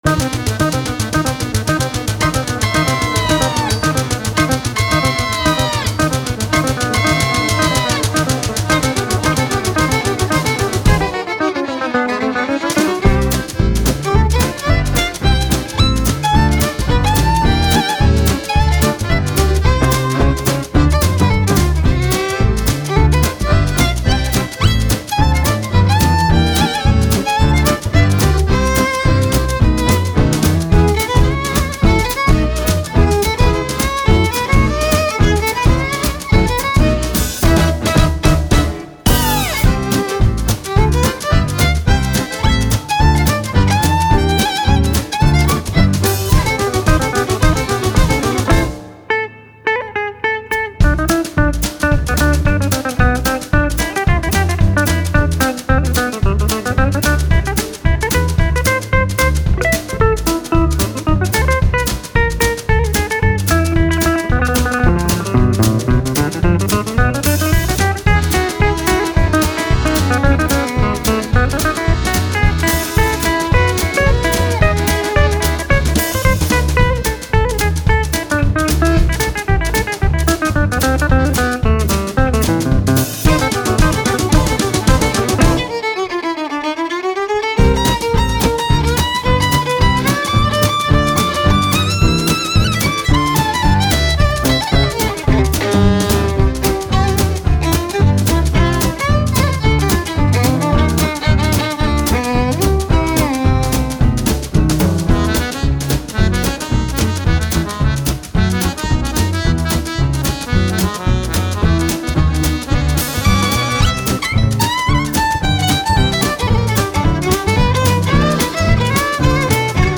Guitar/D'rbukka
Violin/Viola
Accordian
Double Bass
Drums